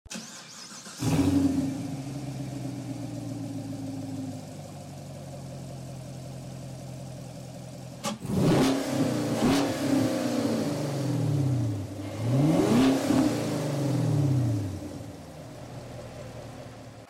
Chevrolet Camaro SS 2016 (N500).